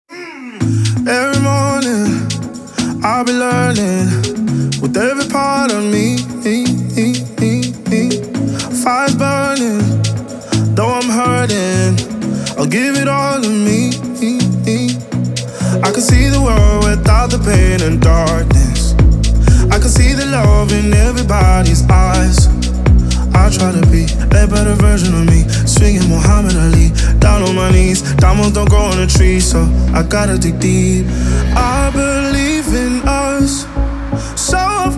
Жанр: Танцевальные